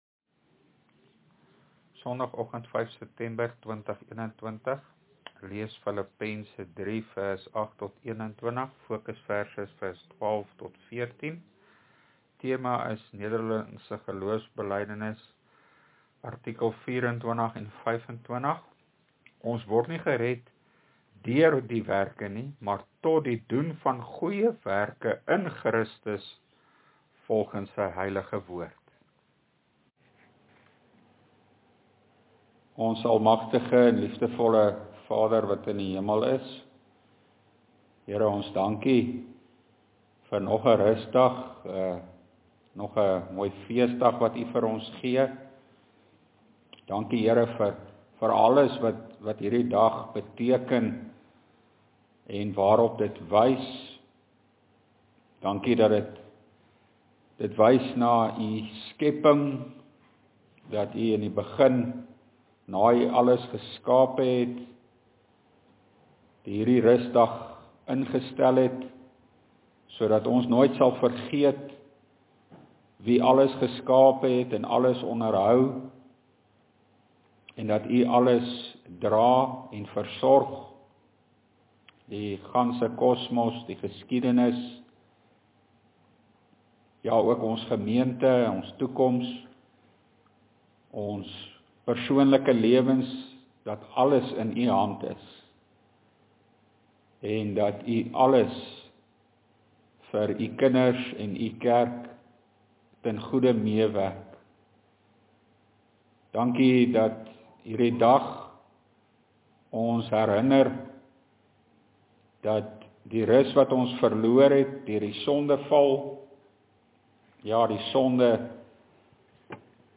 LEERPREDIKING: NGB artikel 24&25